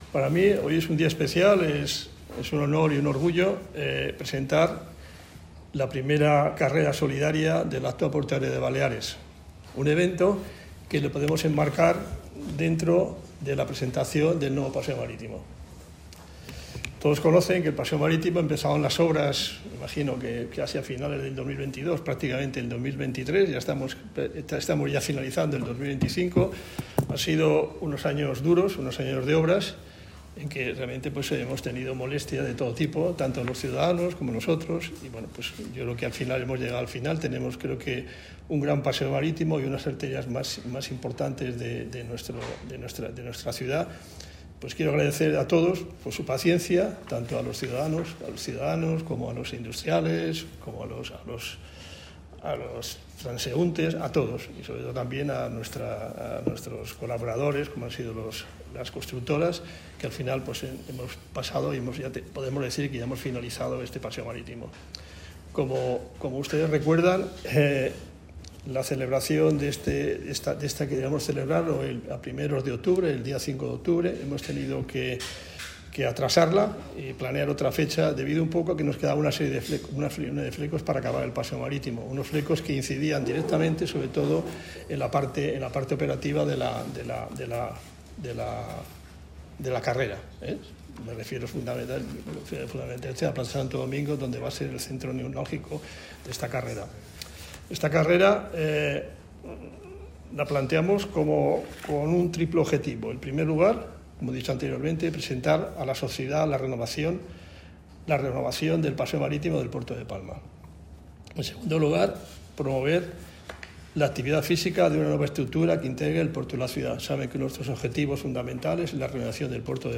Cursa solidària - declaracions president.mp3